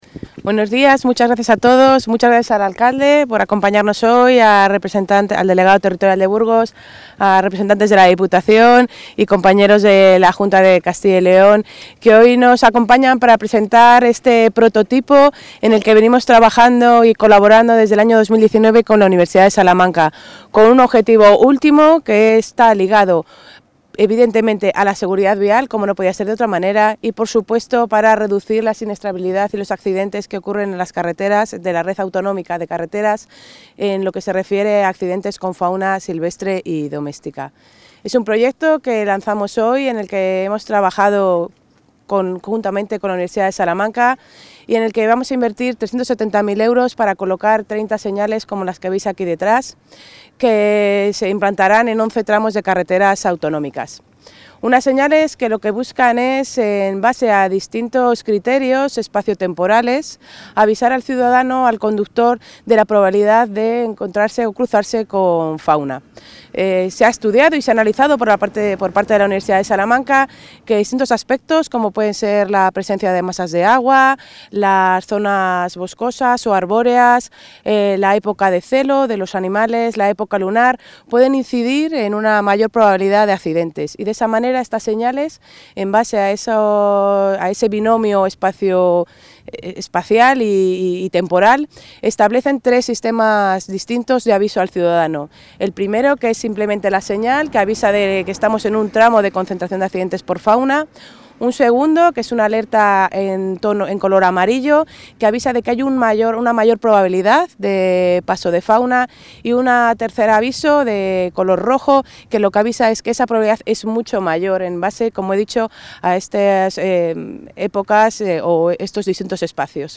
Intervención de la consejera de Movilidad y Transformación Digital.